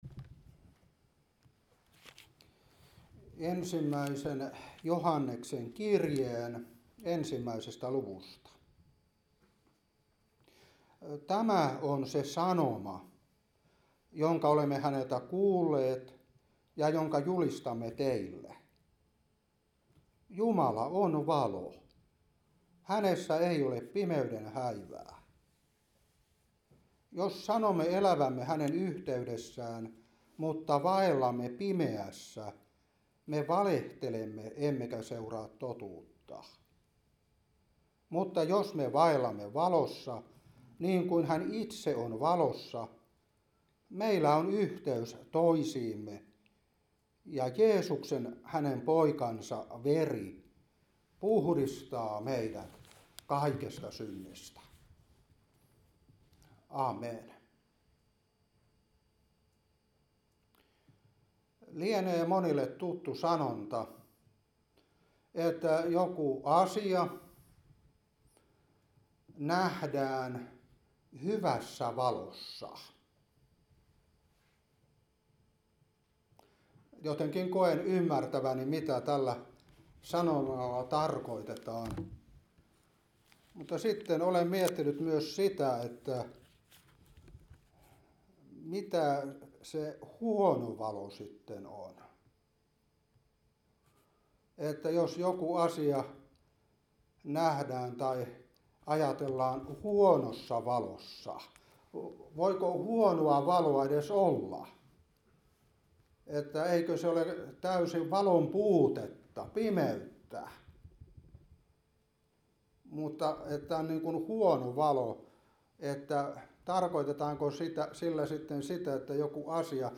Seurapuhe 2026-2 1.Joh.1:5-7